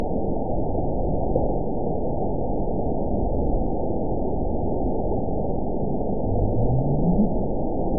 event 912433 date 03/26/22 time 20:49:05 GMT (3 years, 1 month ago) score 9.60 location TSS-AB04 detected by nrw target species NRW annotations +NRW Spectrogram: Frequency (kHz) vs. Time (s) audio not available .wav